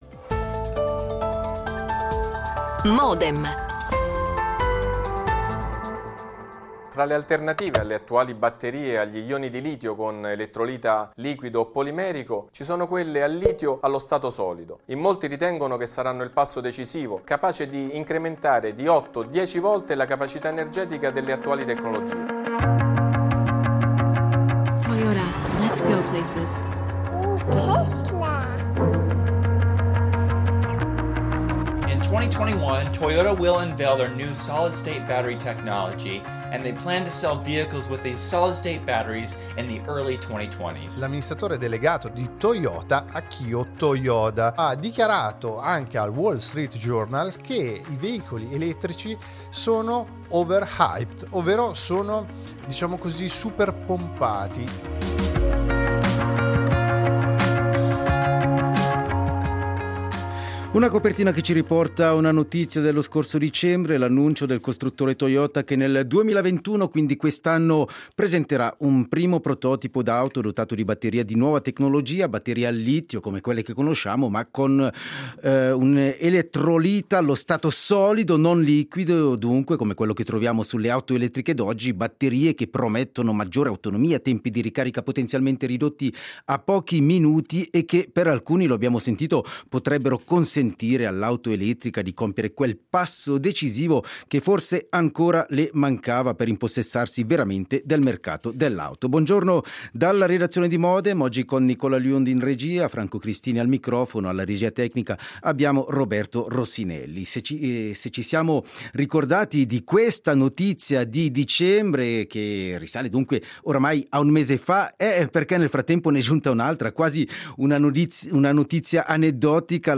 Intervista registrata
L'attualità approfondita, in diretta, tutte le mattine, da lunedì a venerdì